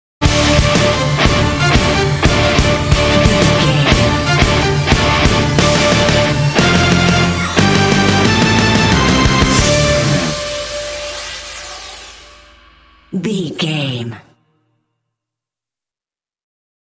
Aeolian/Minor
intense
driving
aggressive
drums
strings
electric guitar
bass guitar
symphonic rock